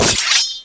FIGHT-steelsword
Tags: combat